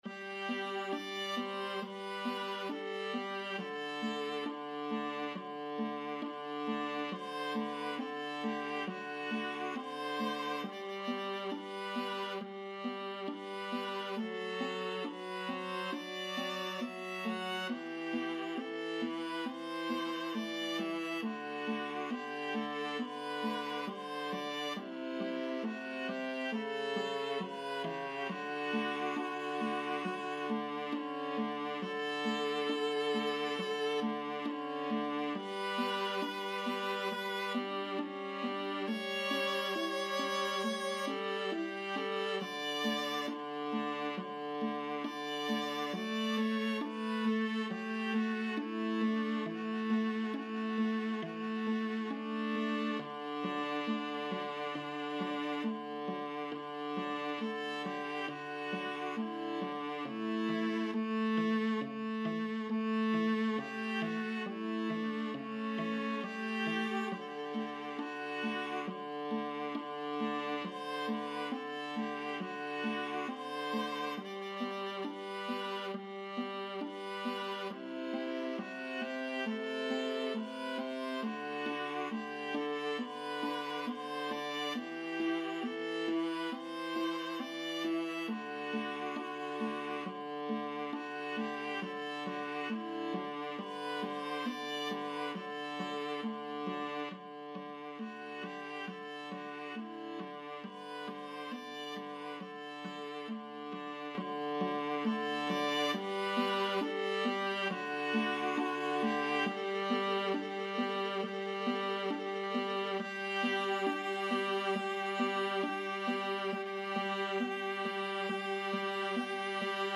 = 34 Grave
4/4 (View more 4/4 Music)
Classical (View more Classical Viola Trio Music)